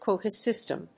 アクセントquóta sỳstem